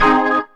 B3 GMAJ 2.wav